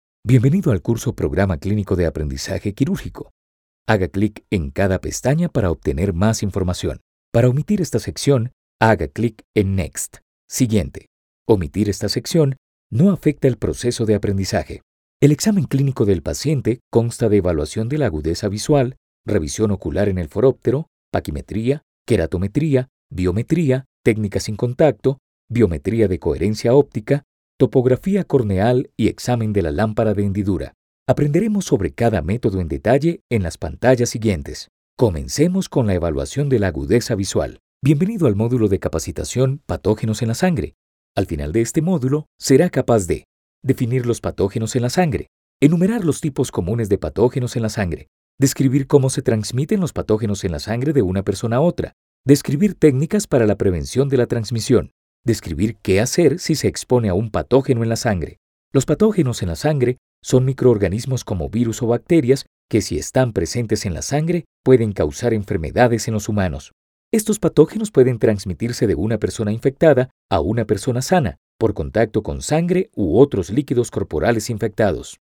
Male
Spanish (Latin American), Spanish (Colombia)
warm, natural, people next door, promotional, institutional, corporate, tradicional announcer, friendly, narrator, trailer and characters.
Medical Narrations
All our voice actors have professional broadcast quality recording studios.
0421Health_Recording.mp3